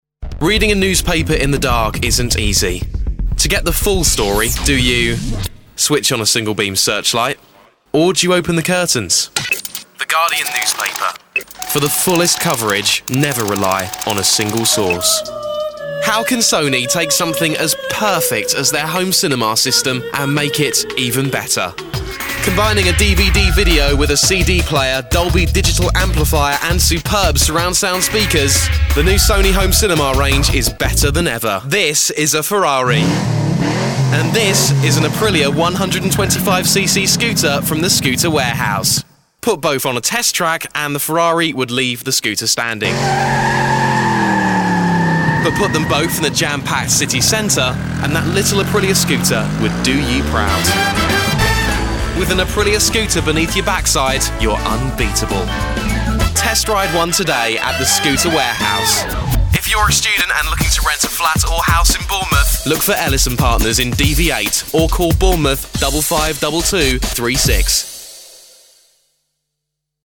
British Male Voice Overs
20s, 30s, Narration, Corporate, Radio Imaging, Trailers, Commercials & Announcements.
PROFESSIONALLY RECORDED VOICE OVERS